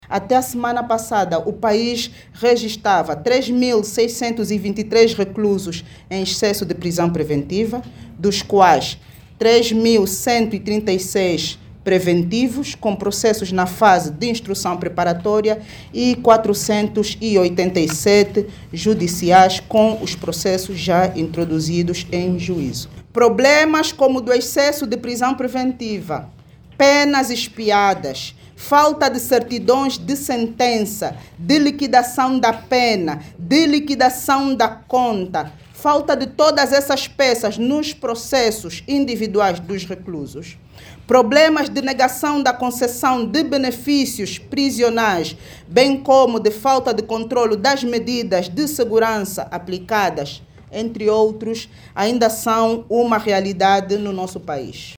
A informação foi avançada pela Procuradora-Geral Adjunta da República, durante o workshop sobre formação em prazos de prisão preventiva, execução das penas, medidas de segurança e direito penitenciário que decorre em luanda.